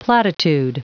Prononciation du mot platitude en anglais (fichier audio)
Prononciation du mot : platitude